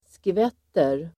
Uttal: [skv'et:er]